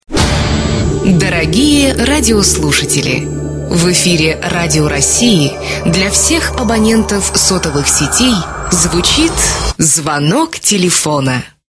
Музыка mp3 "Приколы"